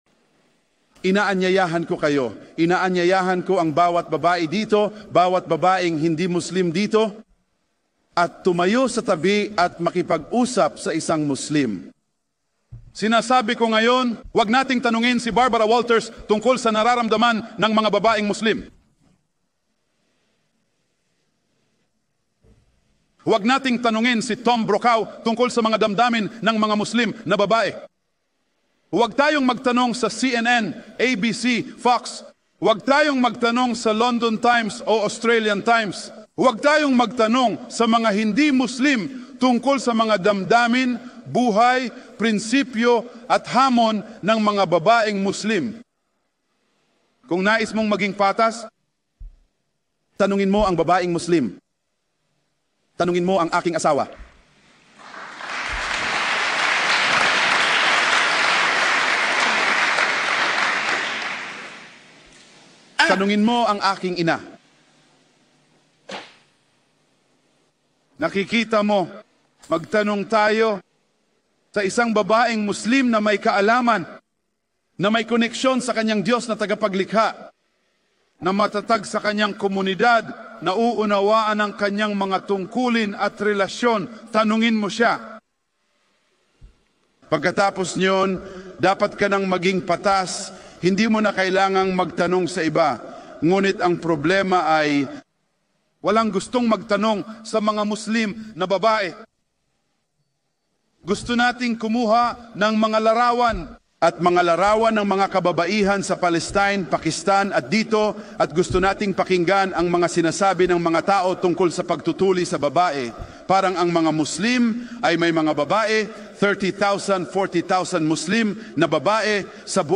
Ang video ay isang magandang bahagi mula sa isa sa mga lektura